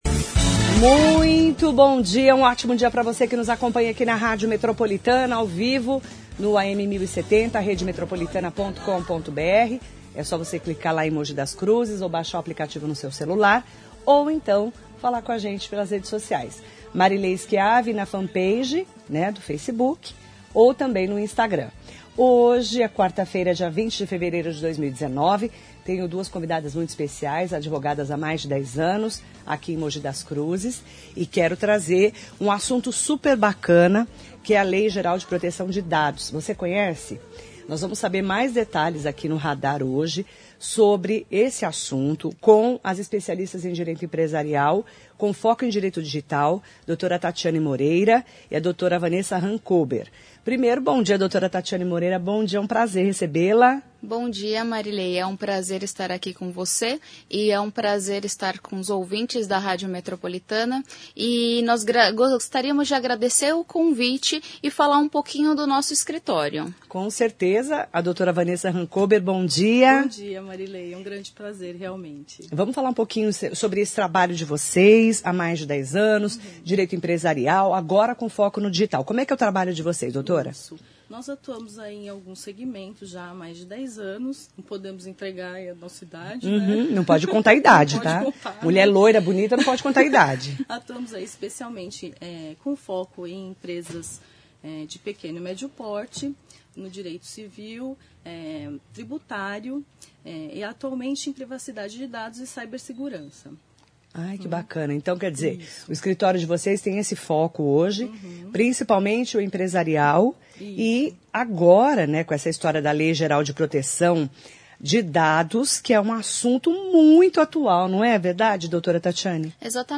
Você conhece a Lei Geral de Proteção de Dados? Saiba os detalhes na entrevista com as advogadas especialistas em Direito Empresarial